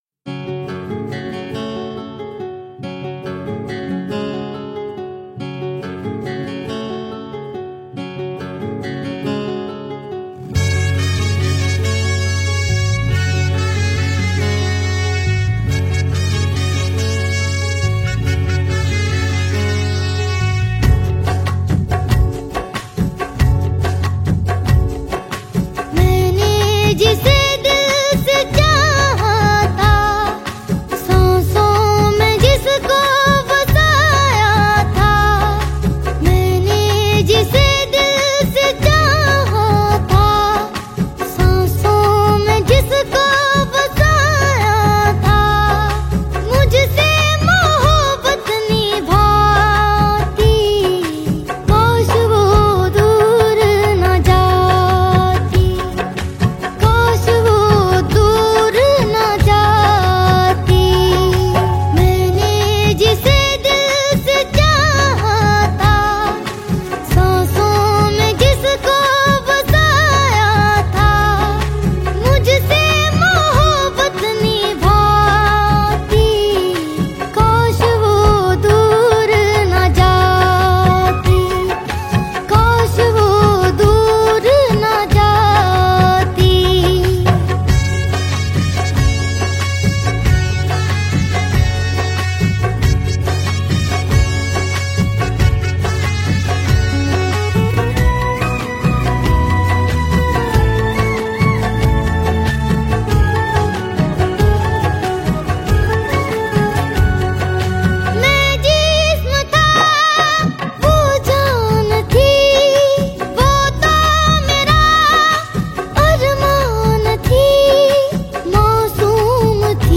Hindi Pop